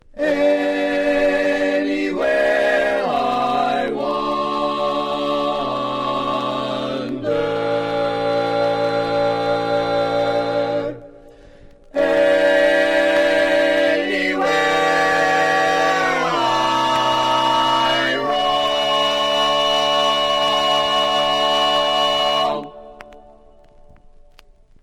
Key written in: D Major
How many parts: 4
Type: Barbershop
All Parts mix: